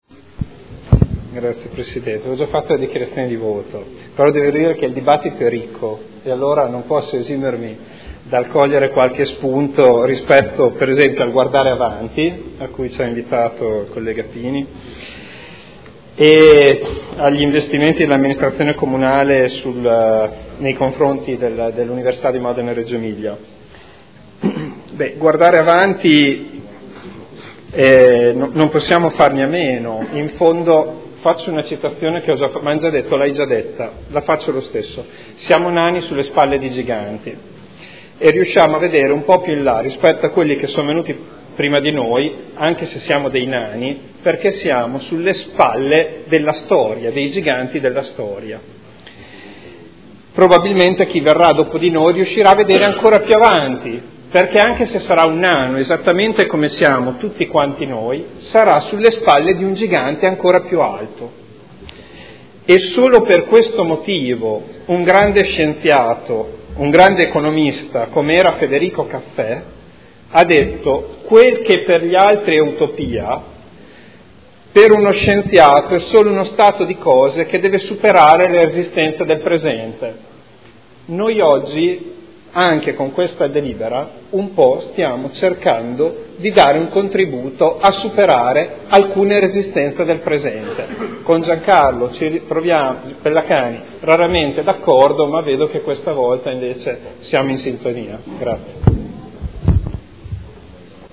Seduta del 31 marzo. Proposta di deliberazione: Proposta di progetto - Ampliamento del Centro Medicina Rigenerativa – Via Gottardi – Z.E. 473 area 01 – Nulla osta in deroga agli strumenti urbanistici comunali – Art. 20 L.R. 15/2013. Dichiarazioni di voto